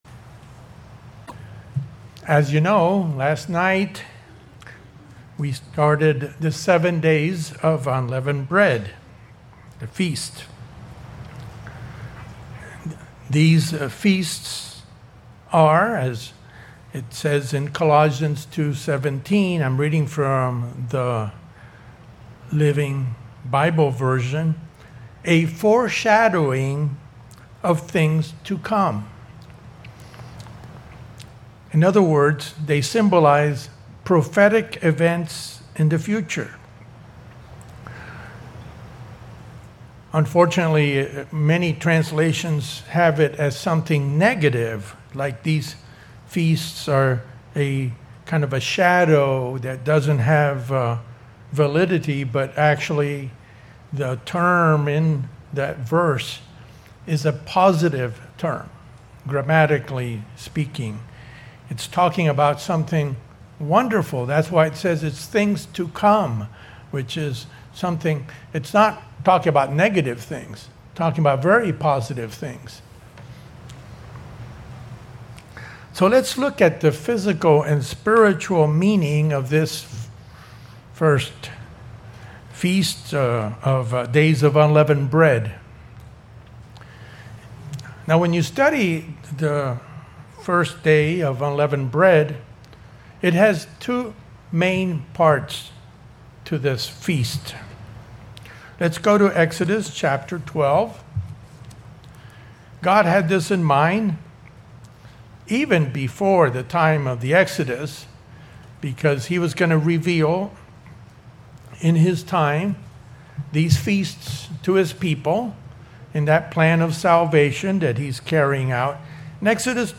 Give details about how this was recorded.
This message given on the First Day of Unleavened Bread focuses on both the physical and spiritual meaning of this week-long feast. The spiritual meaning of leaven and unleaven is presented with biblical scriptures which contrast the sins of human nature with the fruits of the Spirit.